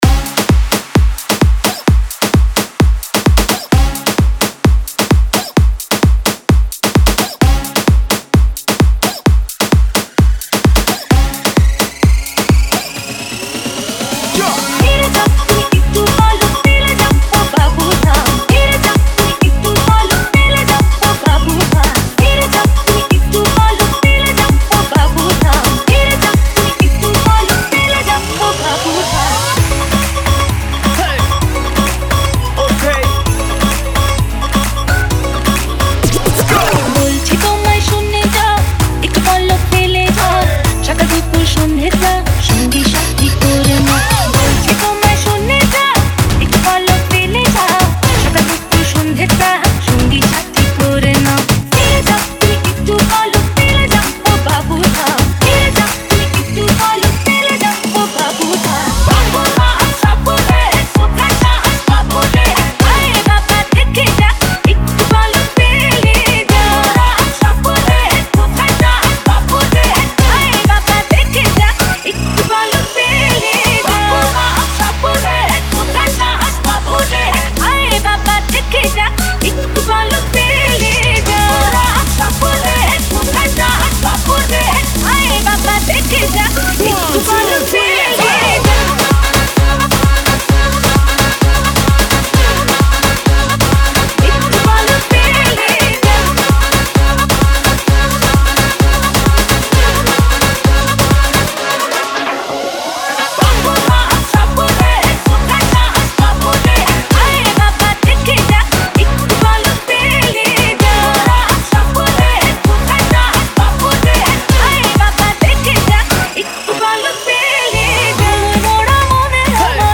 CIRCUIT MIX